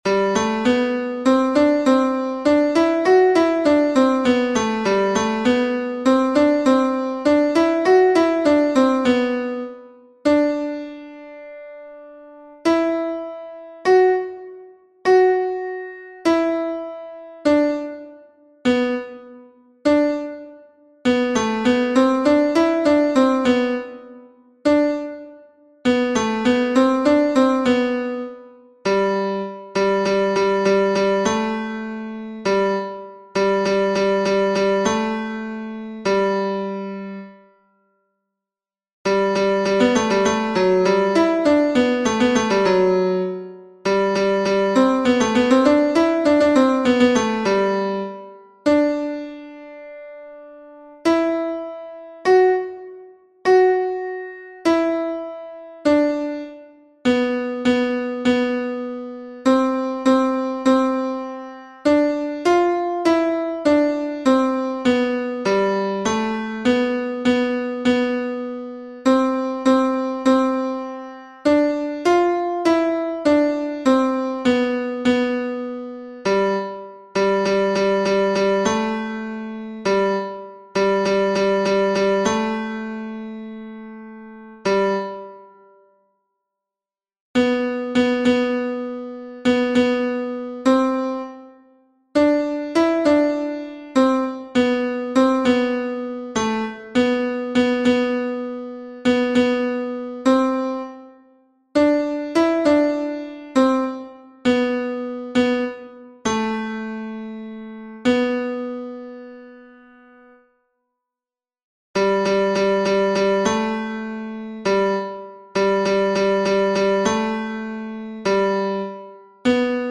tenors-mp3 23 juin 2021